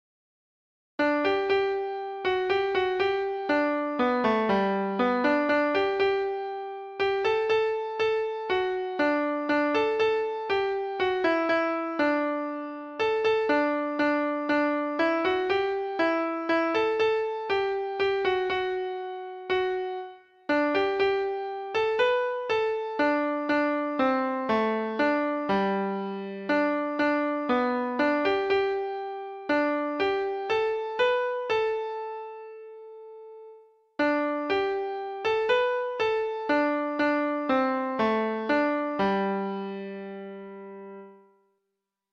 Folk Songs from 'Digital Tradition' Letter F Fathom The Bowl
Treble Clef Instrument  (View more Intermediate Treble Clef Instrument Music)
Traditional (View more Traditional Treble Clef Instrument Music)